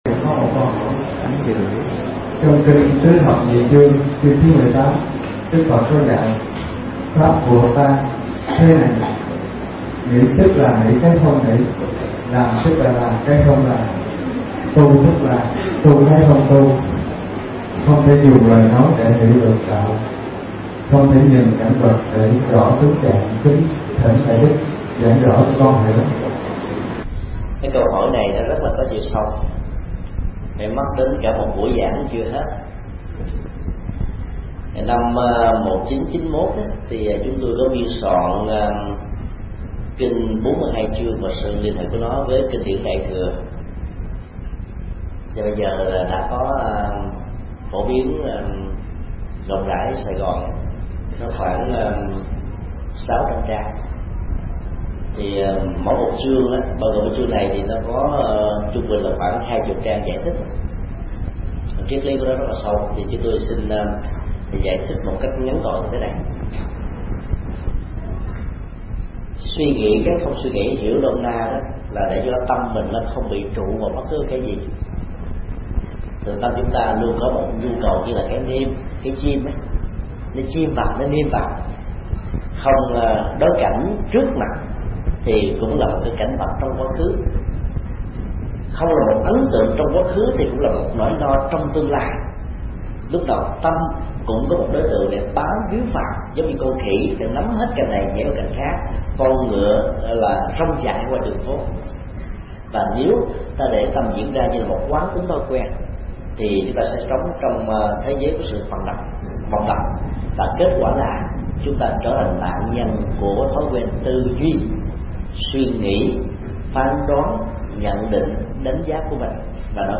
Vấn đáp: Suy nghĩ cái không suy nghĩ – Thầy Thích Nhật Từ mp3